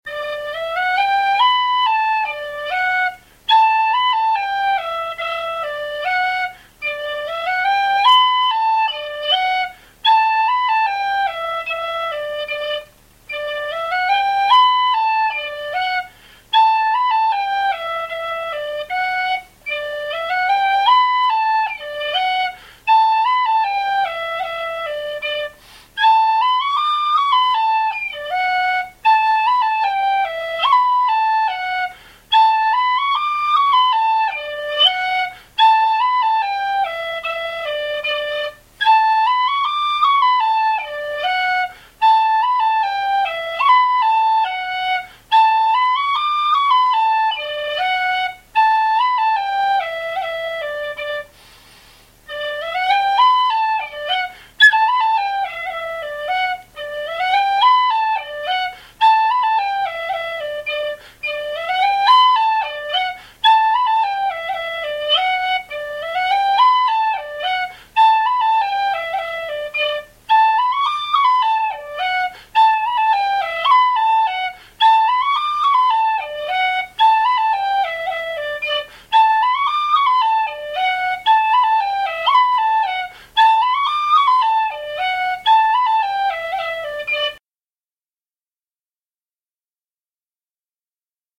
I've Got a Bonnet Trimmed in Blue polka D pdf 14KB txt